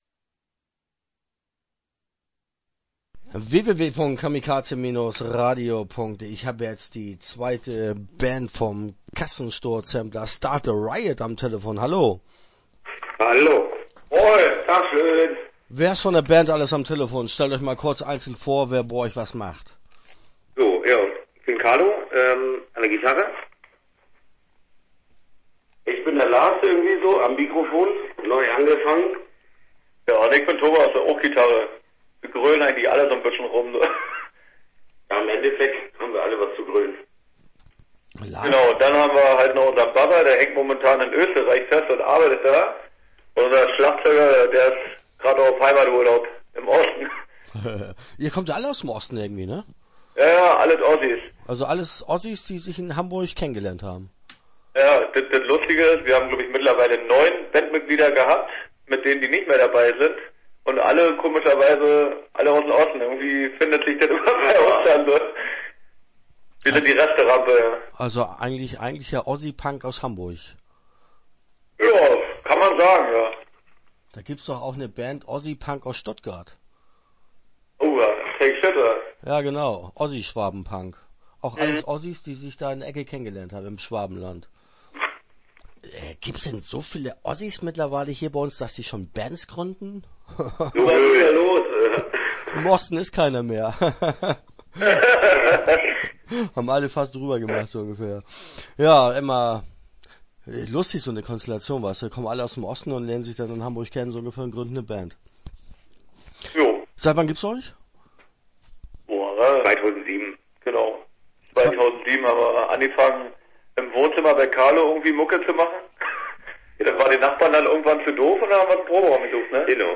Start a Riot - Interview Teil 1 (10:13)